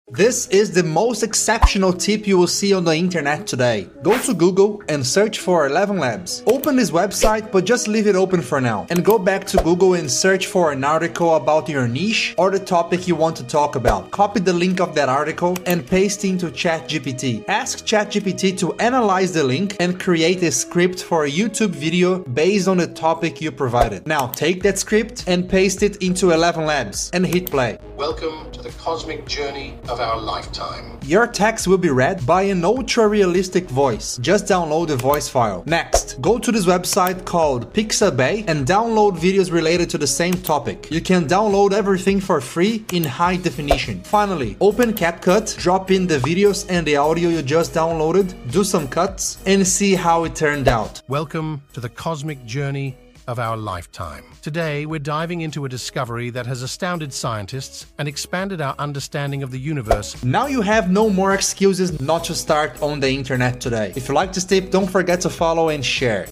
Just look at the level of tools nowadays! The voice Ai tool is incredible, it sounds like a professional narrator.